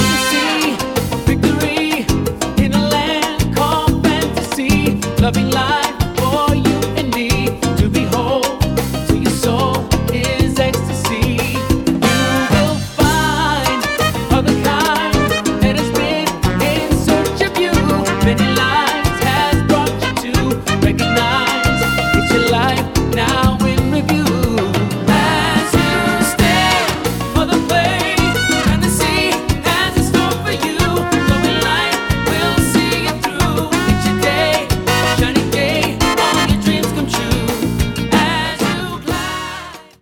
LATIN TROPICAL SOUL EXPERIENCE
vibrant Latin-Tropical-Soul cover